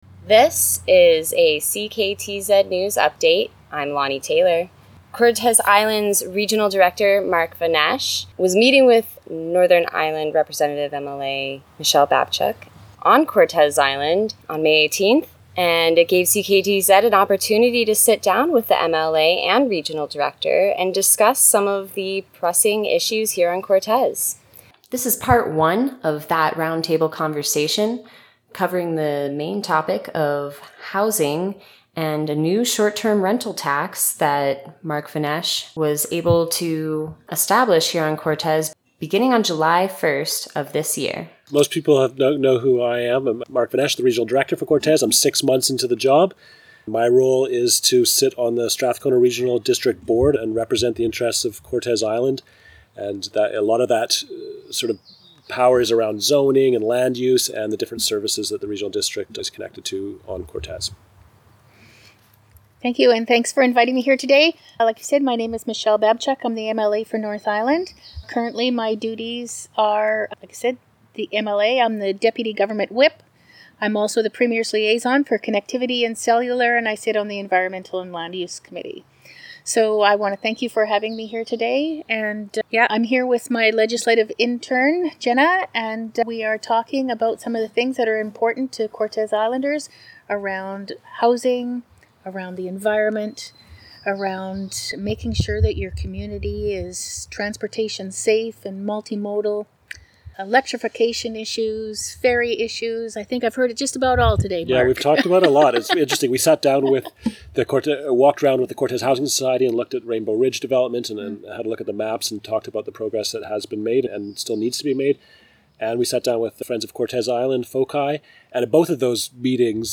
CKTZ had a roundtable chat with SRD Regional Director Mark Vonesch and MLA for North Island Michele Babchuk. This is part one of that discussion.